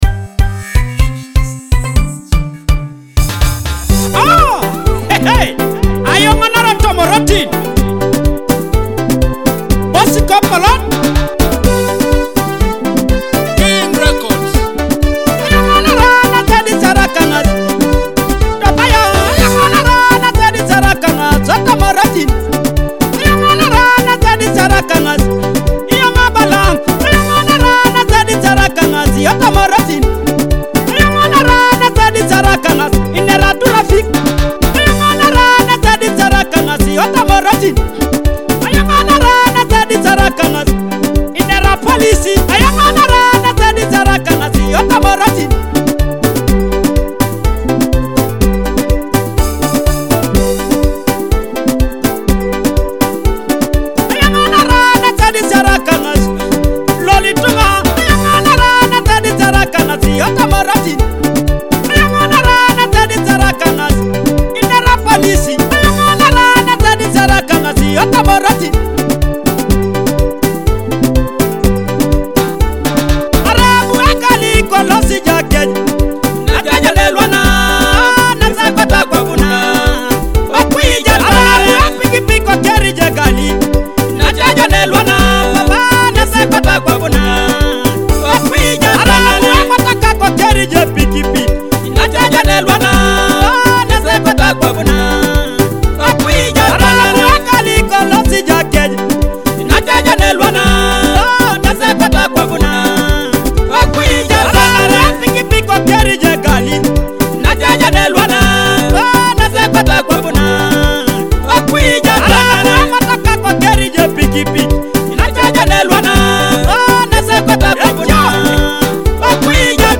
teso traditional music